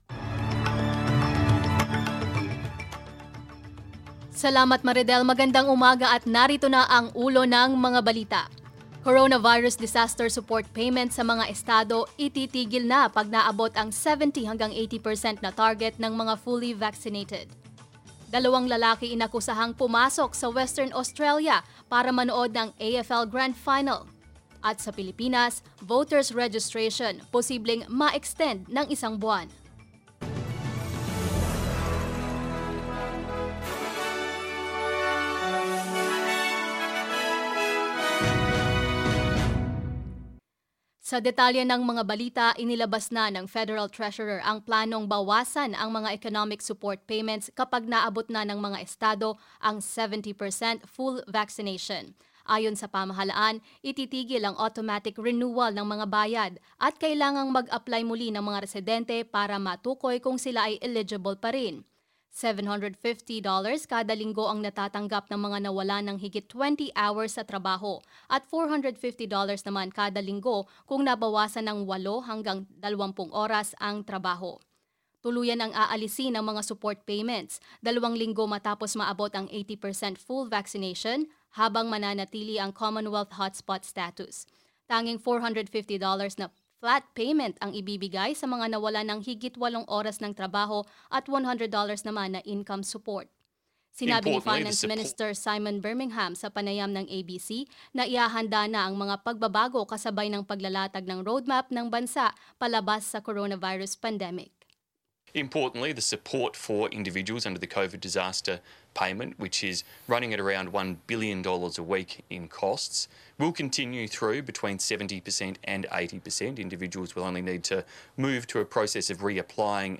SBS News in Filipino, Wednesday 29 September